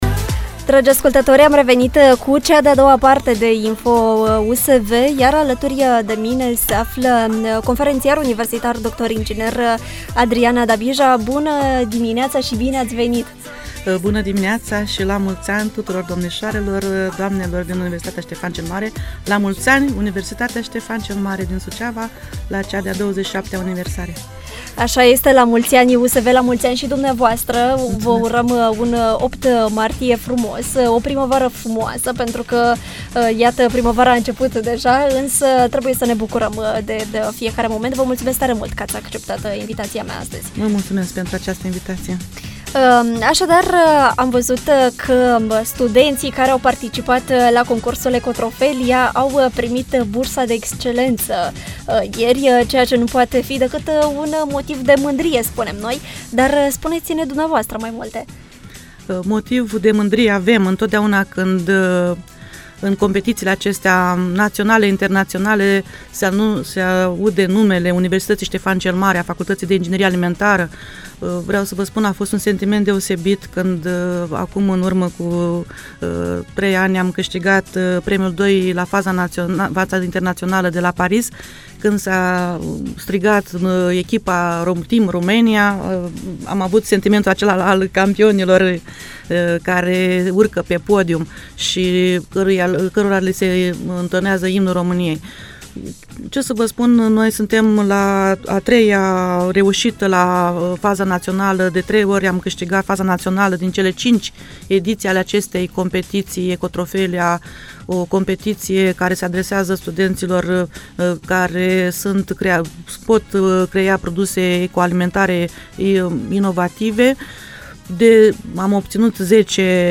Interviul integral îl puteți asculta mai jos